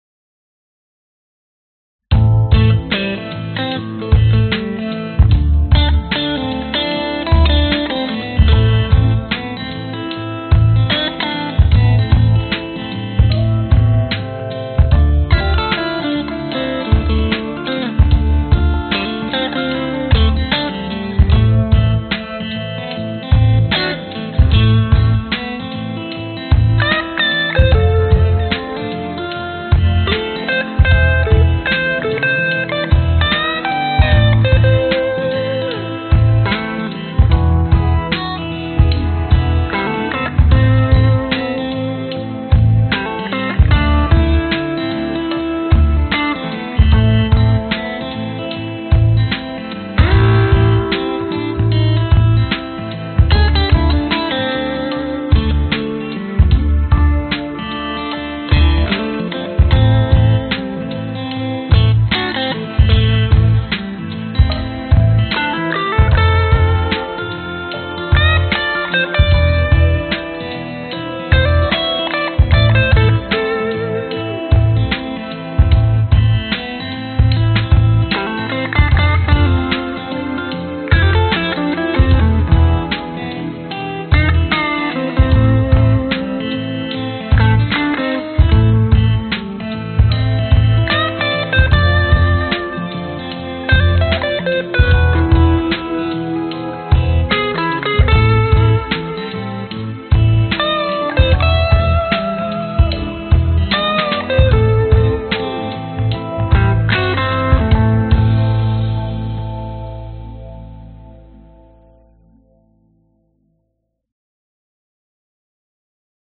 描述：A小调短曲75BPM
Tag: 吉他 钢琴 贝斯 蓝调 视频音乐 电影音乐